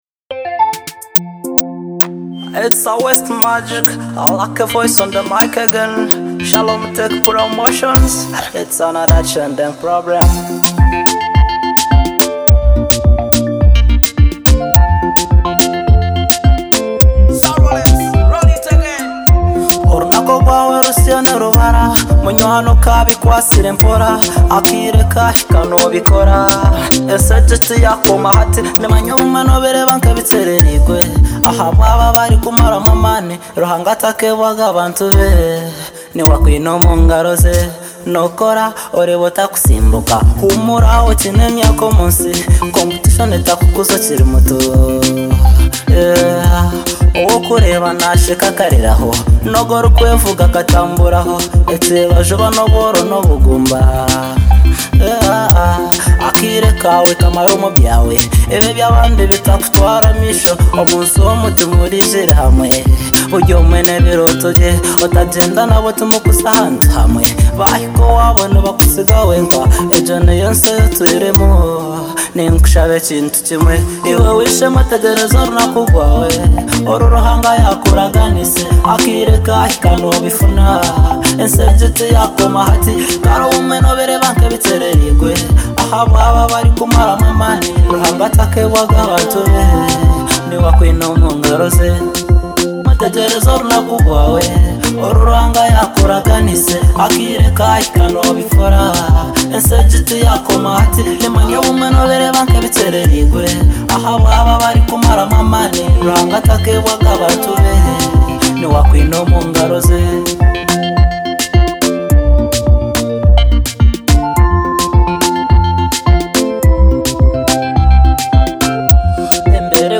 Genre: Afro Pop